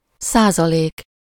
Ääntäminen
US : IPA : [pɚ.ˈsɛn.tɪdʒ]